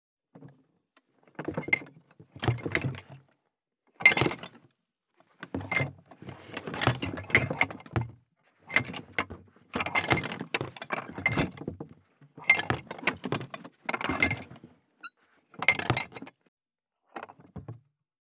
181,ガタガタ,ドスン,バタン,ズシン,カラカラ,ギィ,ゴトン,キー,ザザッ,ドタドタ,バリバリ,カチャン,ギシギシ,ゴン,ドカン,ズルズル,タン,パタン,ドシン,
効果音荷物運び
効果音